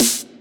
snare.ogg